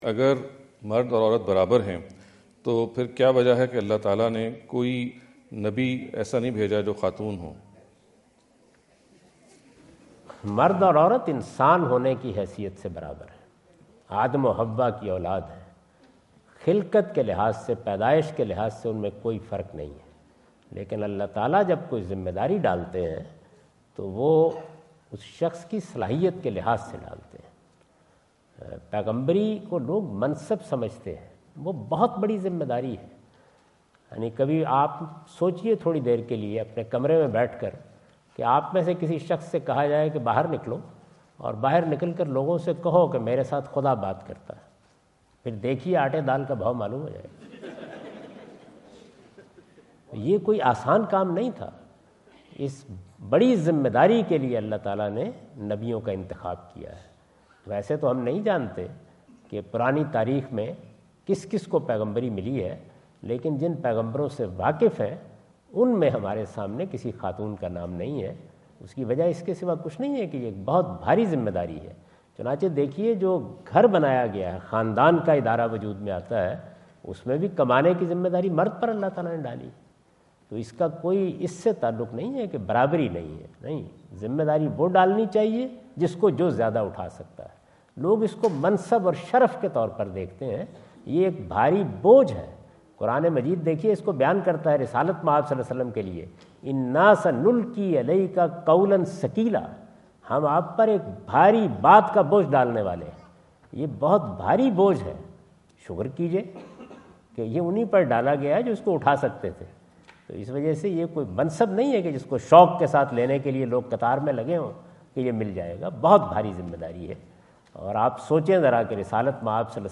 Category: English Subtitled / Questions_Answers /
Javed Ahmad Ghamidi answer the question about "Why All Prophets were Men?" asked at The University of Houston, Houston Texas on November 05,2017.